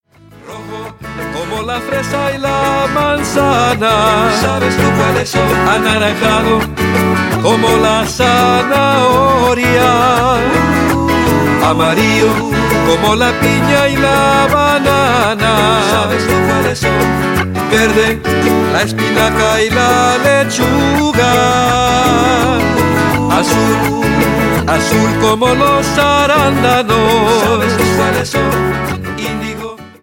lively Spanish children's song